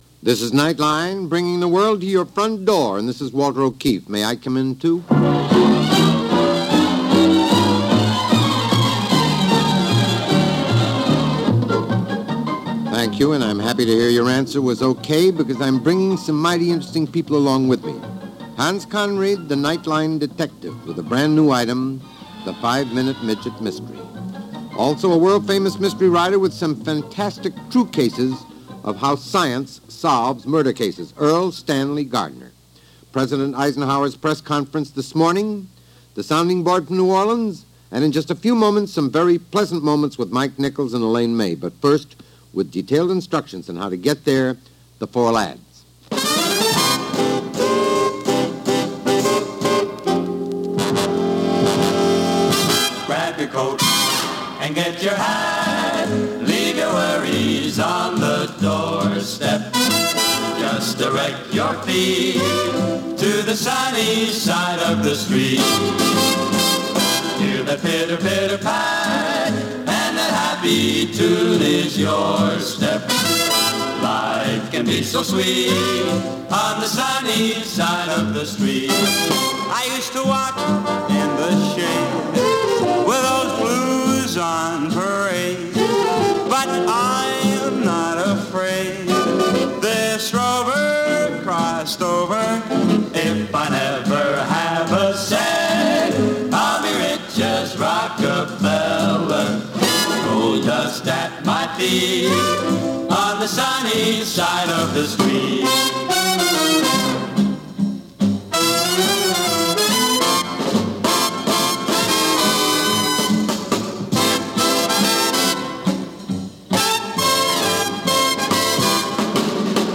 A sampling of what life sounded like via radio and the topics and thoughts of the day.